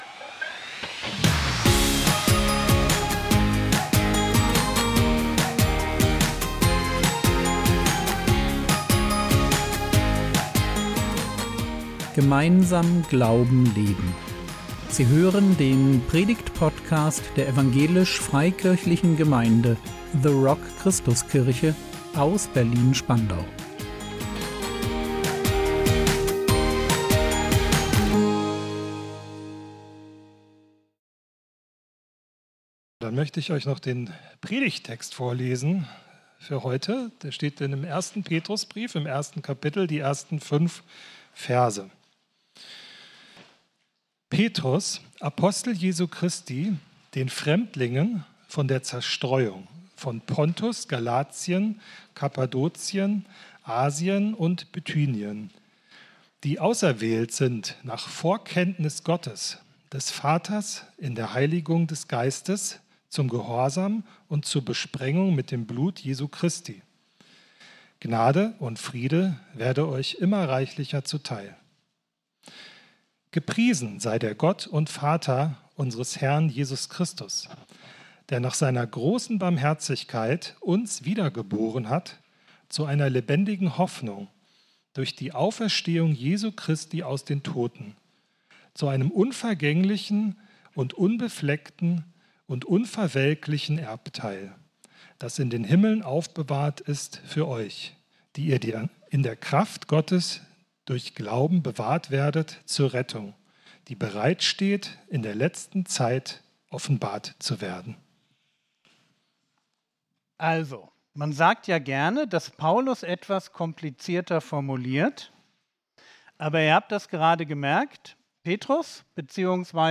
Leben gegen den Strom! | 19.04.2026 ~ Predigt Podcast der EFG The Rock Christuskirche Berlin Podcast